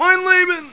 SS_Soldier_DSSSDTH.wav